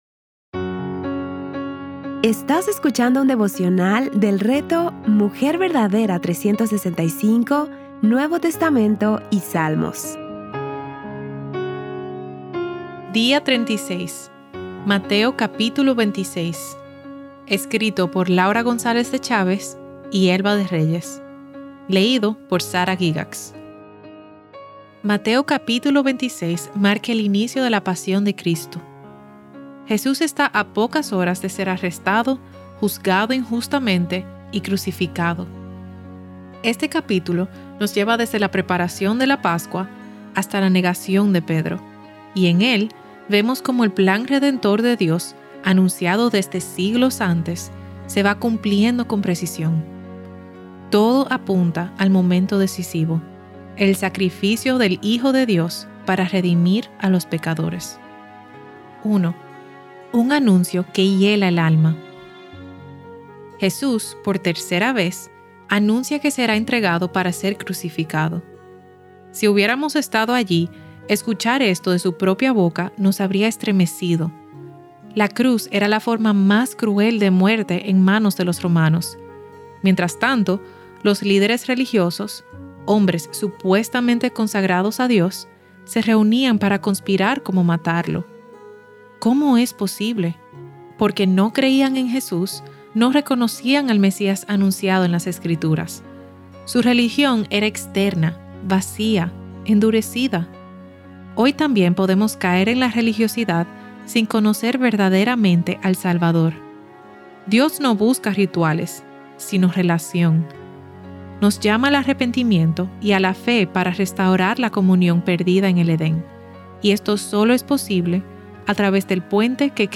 Series:  Mateo y Salmos | Temas: Lectura Bíblica